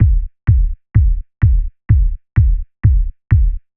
• Tight Kick.wav
Tight_Kick_zR7.wav